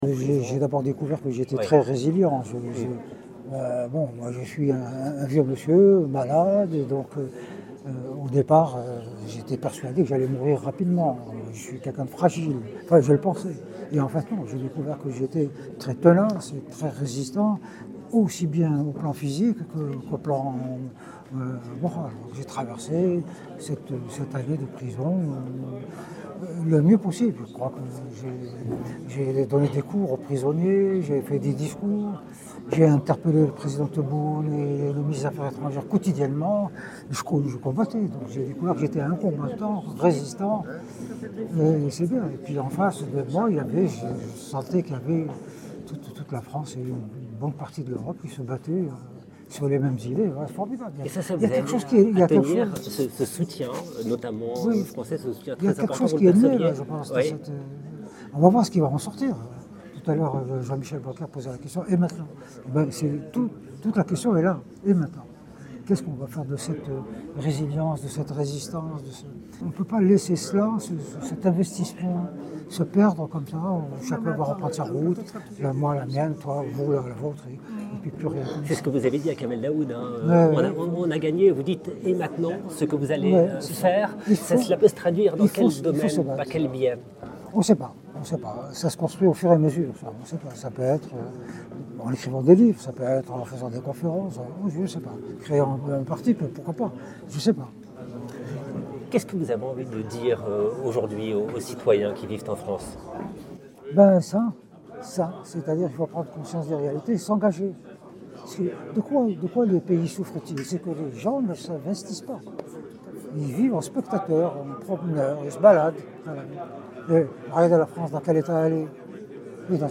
Interview avec Boualem Sansal
Une rencontre à la Maison de l’Amérique latine à Paris où l’écrivain a retrouvé ses amis et membres du comité de soutien co fondé par Arnaud Benedetti.